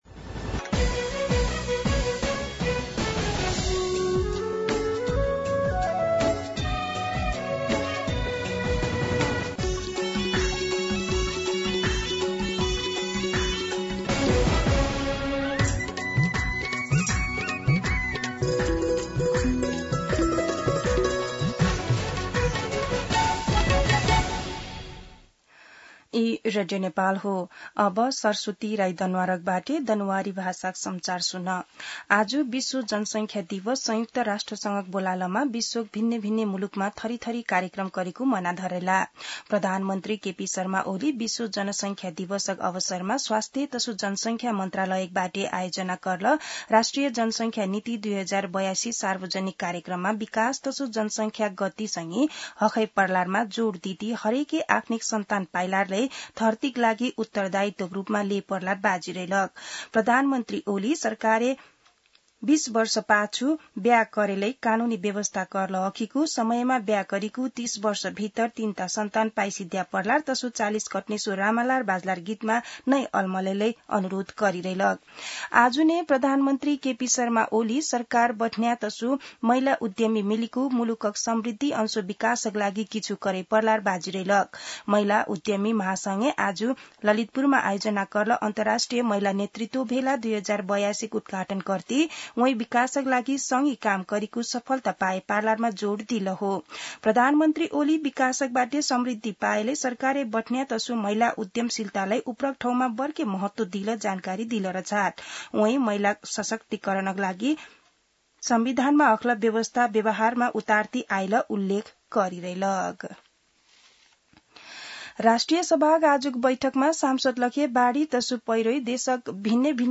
दनुवार भाषामा समाचार : २७ असार , २०८२
Danuwar-News.mp3